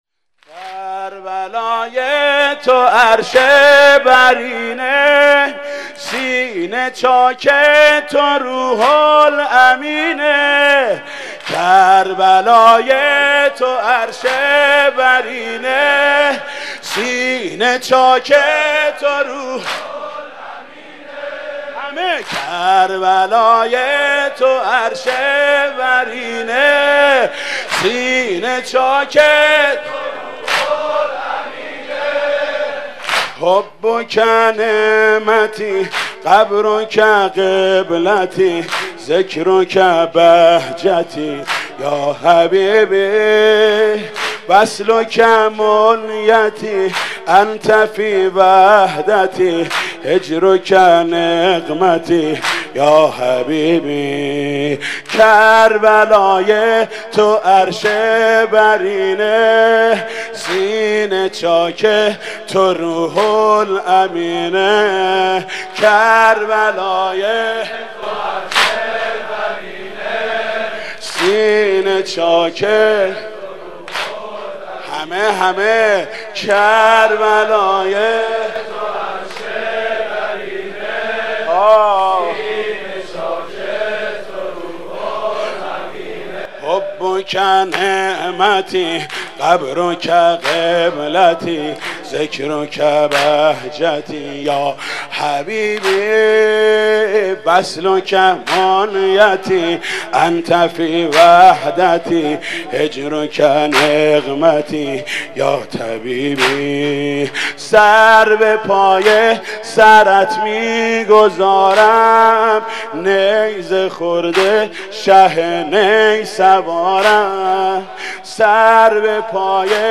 مداحی محمود کریمی